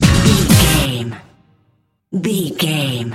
Aeolian/Minor
synthesiser
drum machine
Eurodance